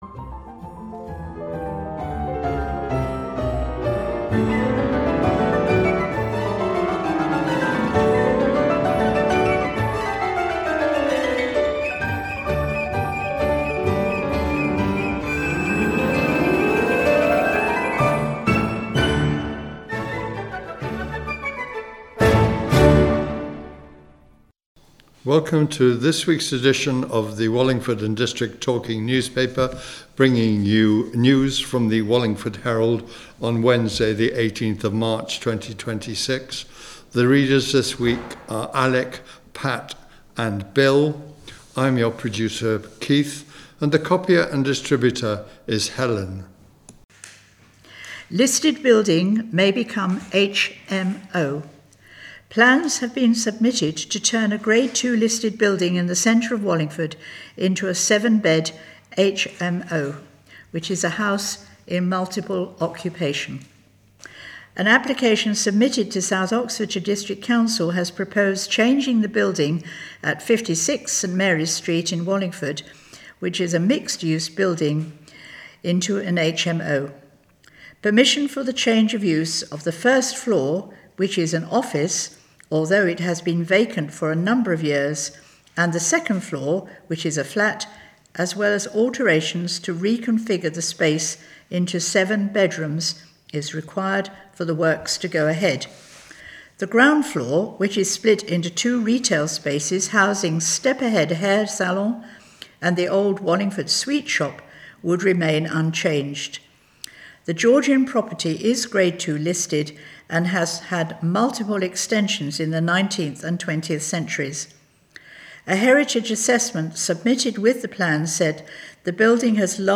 This provides a spoken version of local news for people who are visually impaired or have other problems that make reading a newspaper difficult.